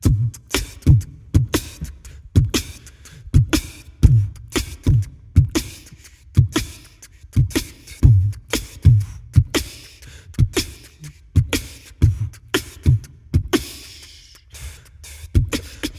Cromatic Tuned Percussion
描述：小七和弦上的调子，带有色差的行进。
Tag: 120 bpm Jazz Loops Percussion Loops 2.70 MB wav Key : Unknown